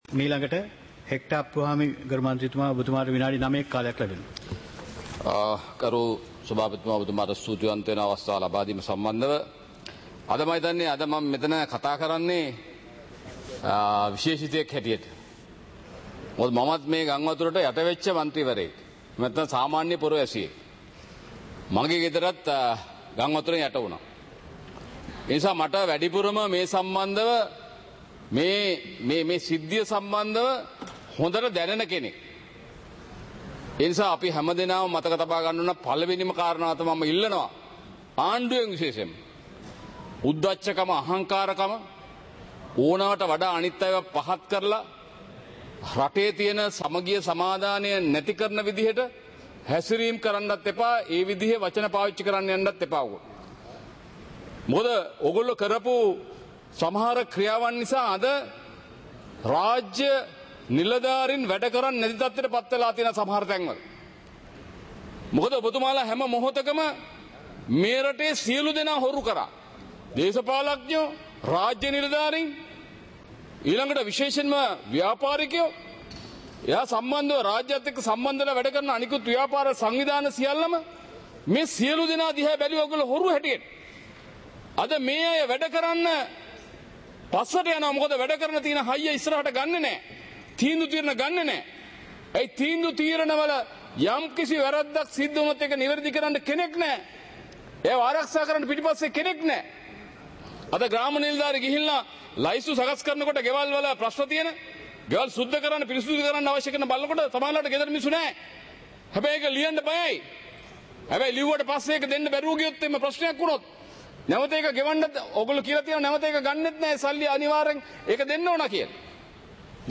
சபை நடவடிக்கைமுறை (2025-12-05)
பாராளுமன்ற நடப்பு - பதிவுருத்தப்பட்ட